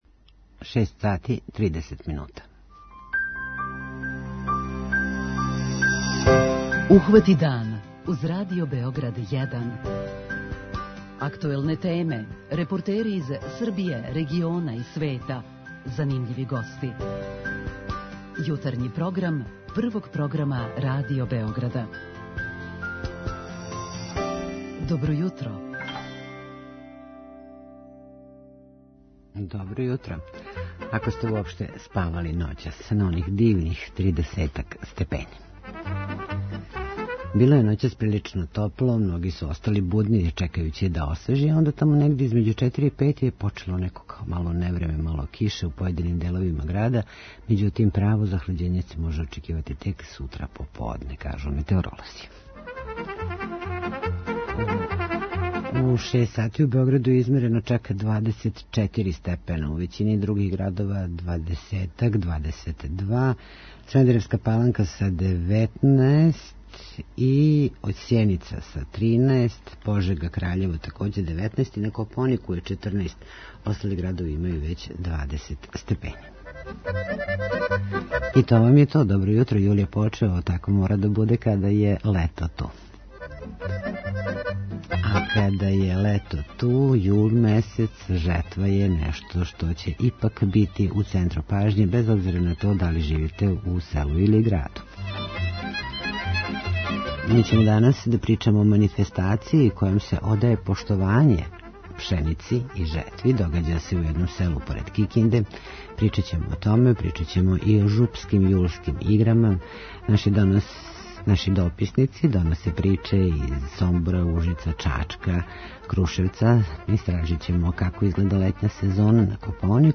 Наши дописници доносе приче из Ужица, Чачка и Крушевца.
преузми : 26.99 MB Ухвати дан Autor: Група аутора Јутарњи програм Радио Београда 1!